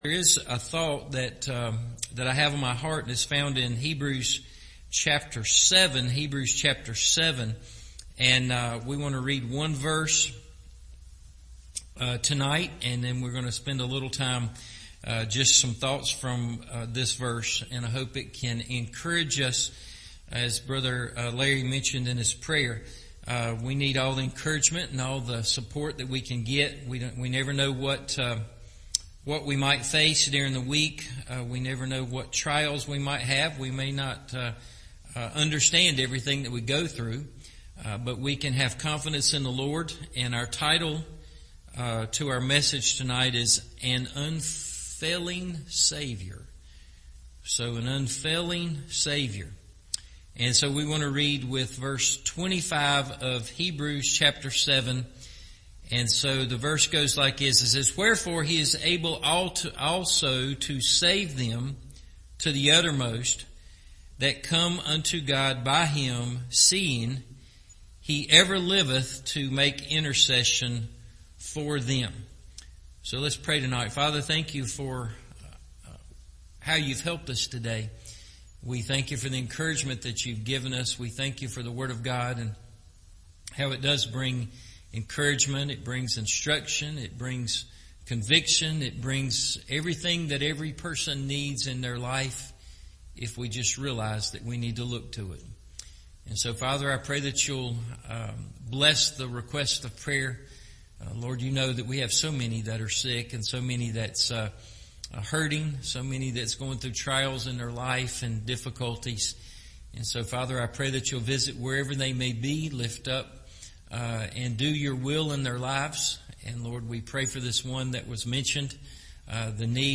An Unfailing Saviour – Evening Service